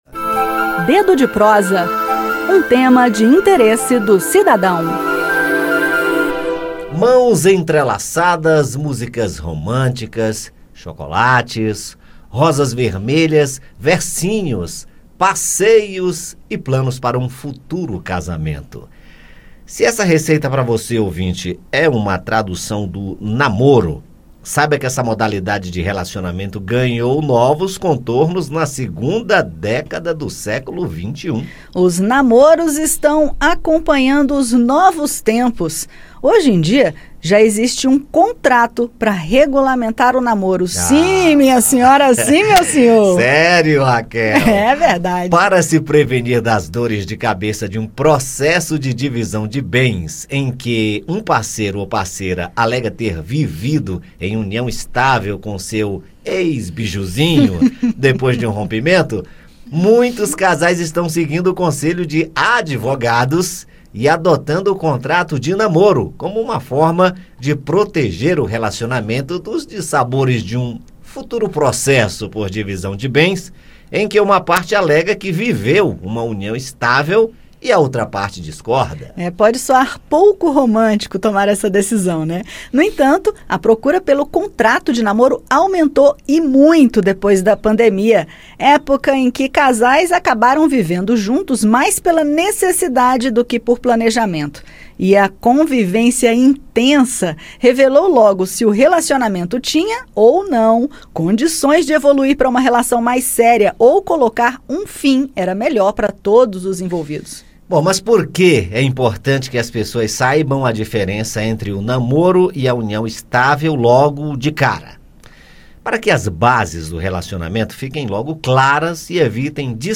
Neste caso, o contrato é o documento que comprova que duas pessoas têm um relacionamento afetivo sem a intenção de constituir uma família. Segundo a justiça, o contrato de namoro visa evitar situações em que uma das partes tire vantagens patrimoniais não combinadas durante a relação. Para entender melhor, ouça o bate-papo